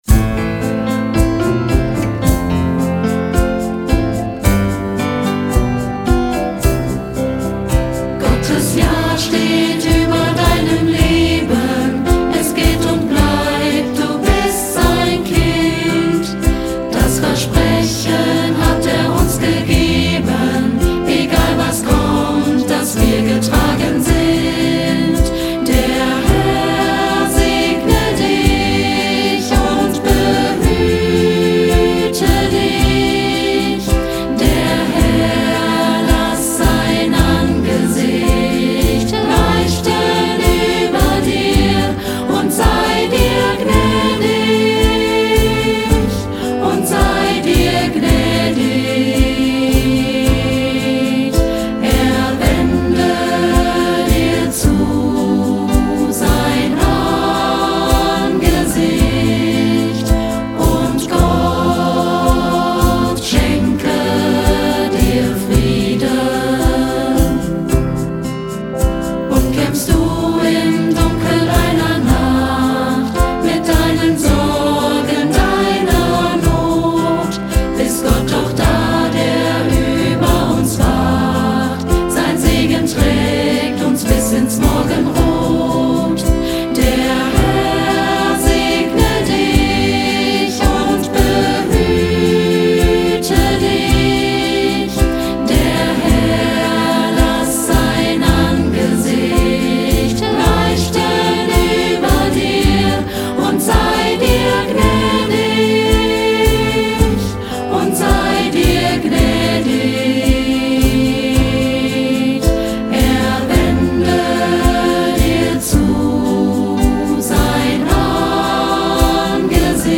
2023 Gesang: Singprojekt „Segensstimme“ Produktion